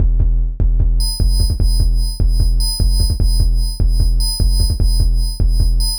It generates a simple sequence of electronic music out of raw wave forms based on hard coded instructions:
🔊 Rhythm
beat.ogg